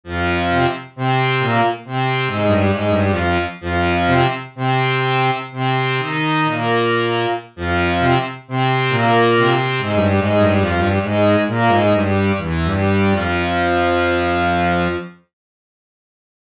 Regał
Są to małe, łatwe do przenoszenia organy, zwykle mające tylko jeden zestaw piszczałek.
Dźwięki instrumentów są brzmieniem orientacyjnym, wygenerowanym w programach:
Kontakt Native Instruments (głównie Factory Library oraz inne biblioteki) oraz Garritan (Aria Player).